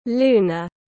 Âm lịch tiếng anh gọi là lunar, phiên âm tiếng anh đọc là /ˈluː.nər/
Lunar /ˈluː.nər/